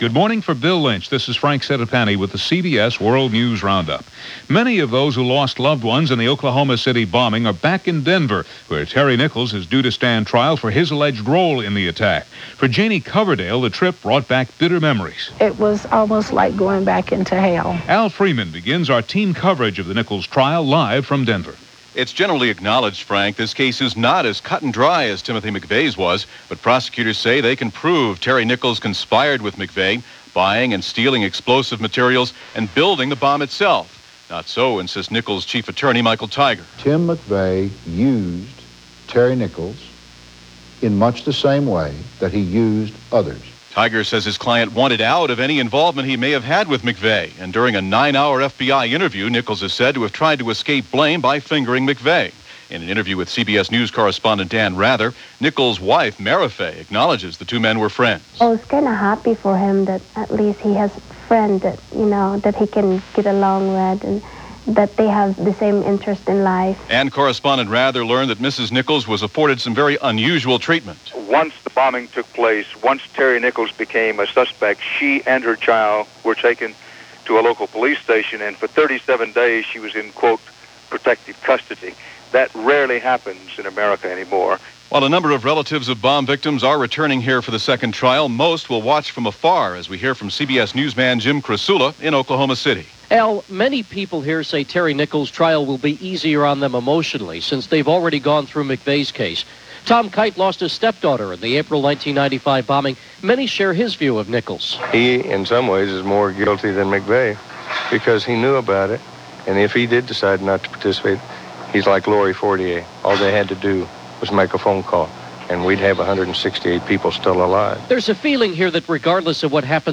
And that’s a small slice of what happened, this September 29, 1997 as reported by The CBS World News Roundup.